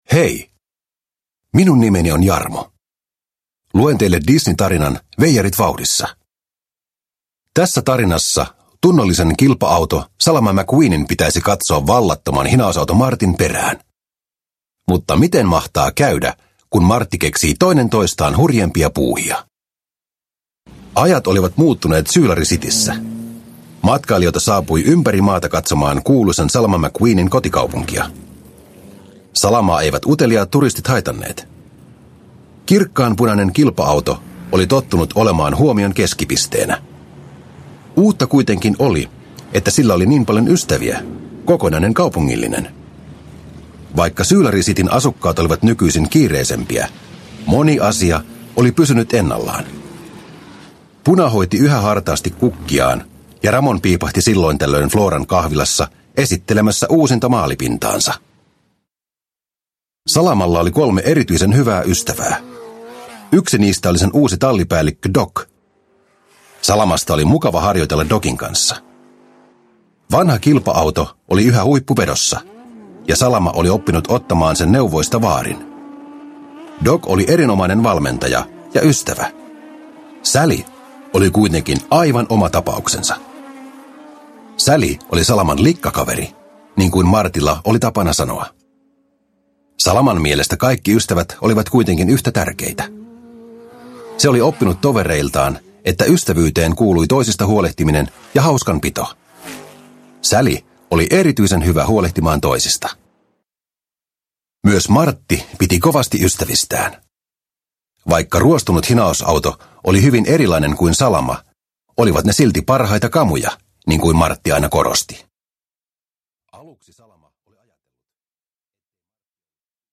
Pixar Autot. Veijarit vauhdissa – Ljudbok – Laddas ner